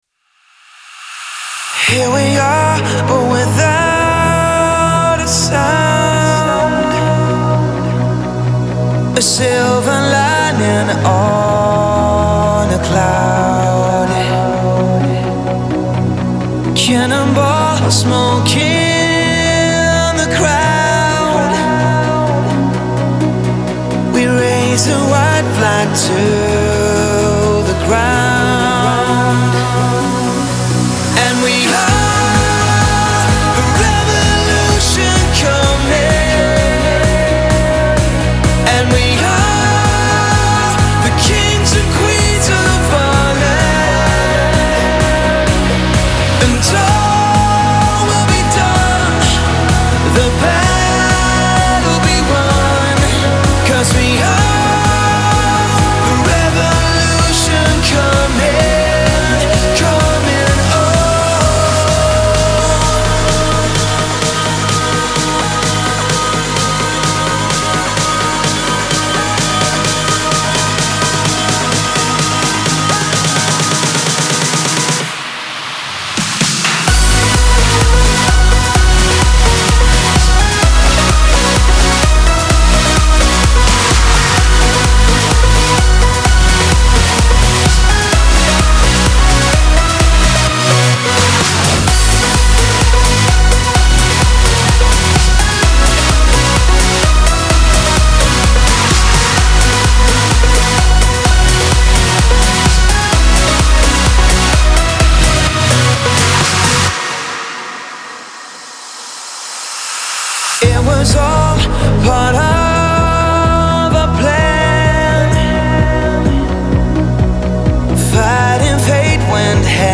Категория: Электро музыка » Транс